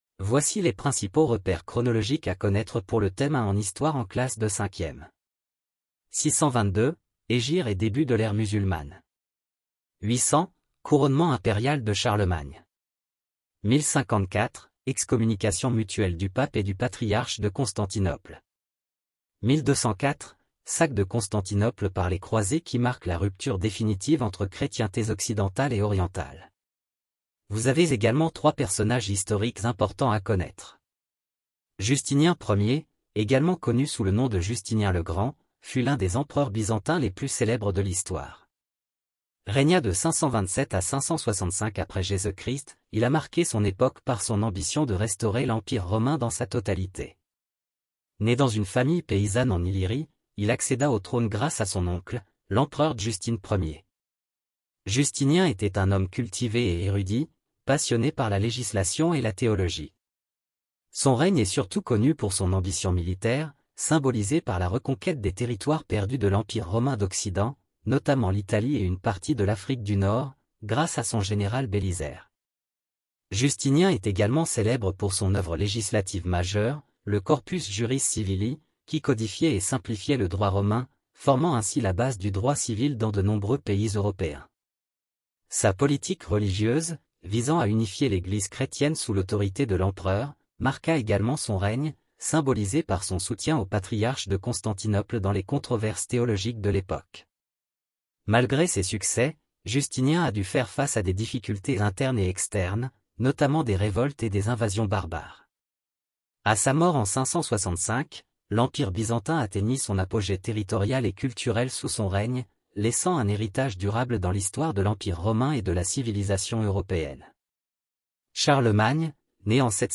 Cours audio 5e histoire